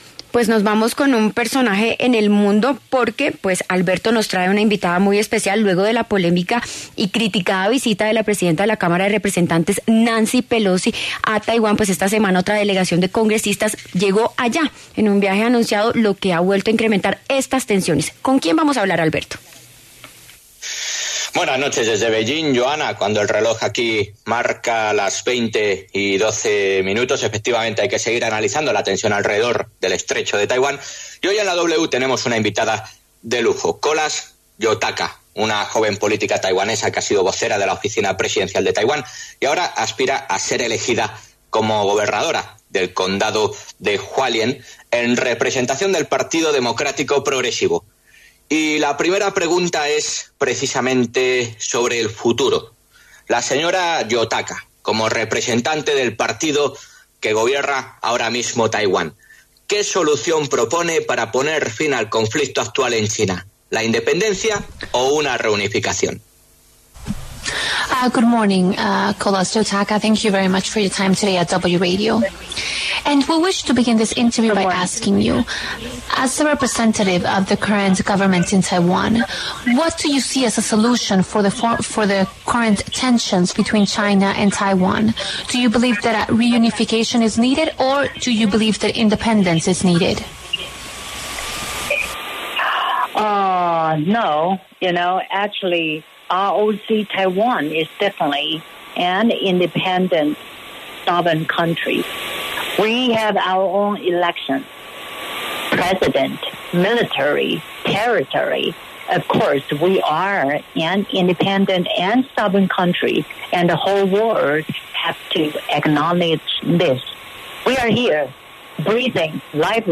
En el encabezado escuche la entrevista completa con Kolas Yotaka, política taiwanesa, exvocera de la Oficina Presidencial de Taiwán y quien actualmente es candidata a gobernadora de Hualien.